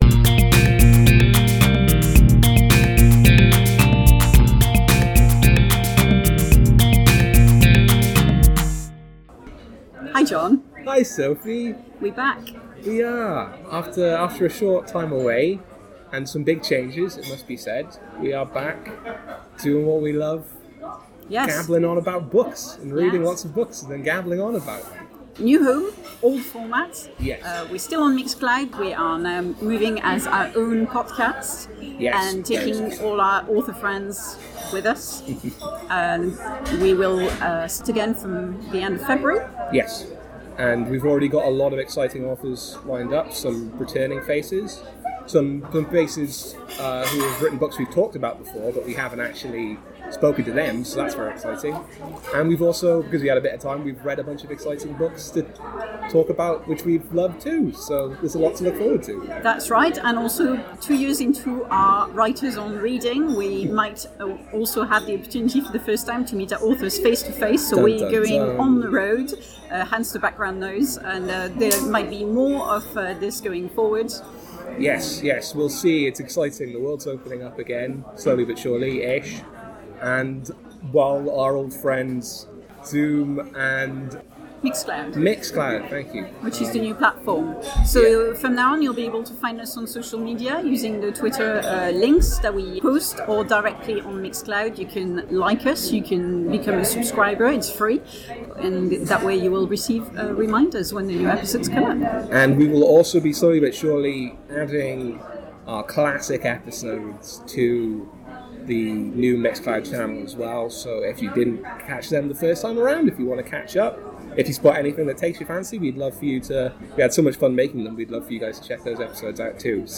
new trailer here.